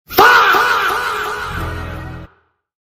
Fahh Sound Effect – Short Comedic Fail Exhale
Download this original Fahh sound effect featuring a short, deep exhale perfect for game fails, comedic mistakes, reaction videos, humorous animations, TikTok, YouTube Shorts, and Instagram Reels.
Fahh-sound-effect.mp3